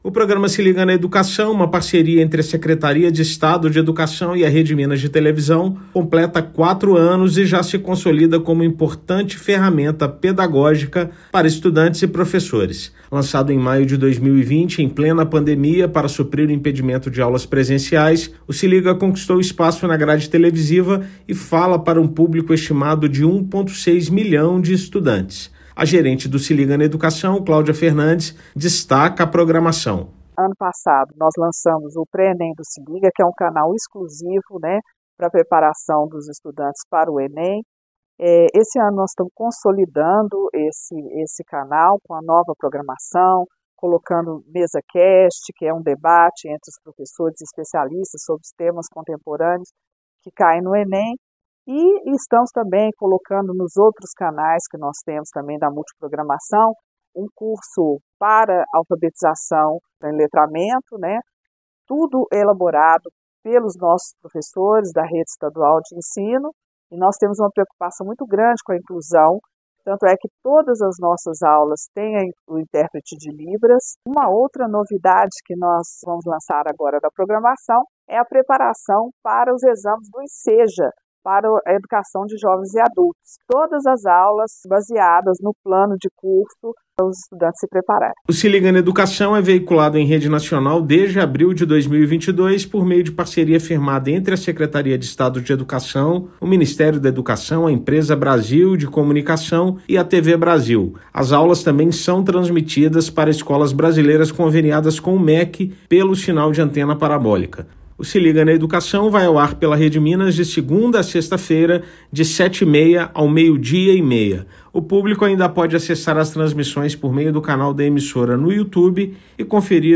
[RÁDIO] “Se Liga na Educação” completa quatro anos e se consolida como importante ferramenta de aprendizagem
Programa transmitido pela Rede Minas foi criado durante a pandemia e segue levando conhecimento a um público estimado de 1,6 milhão de estudantes. Ouça matéria de rádio.